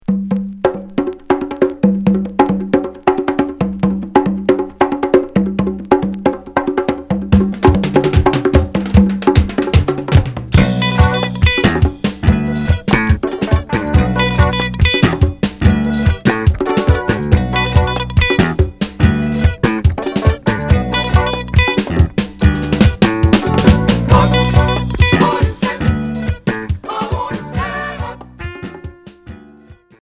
Free rock